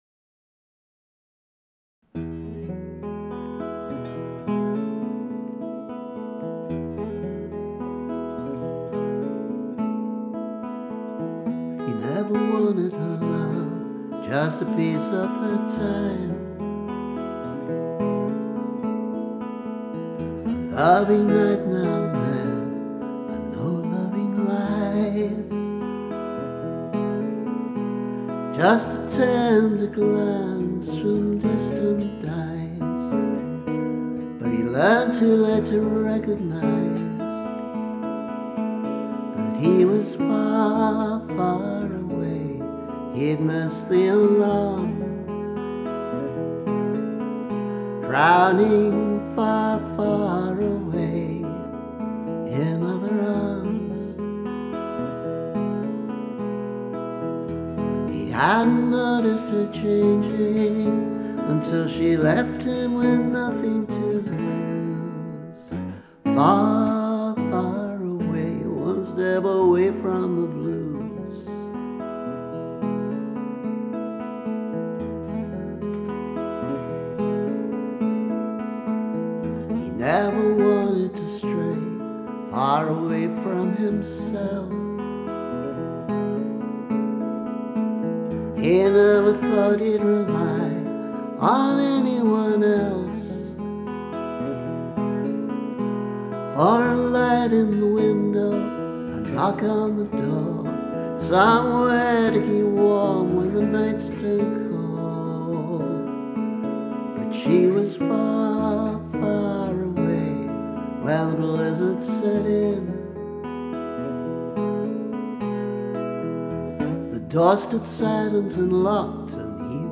Here’s a more recent demo version where the guitar part and vocal seem to work.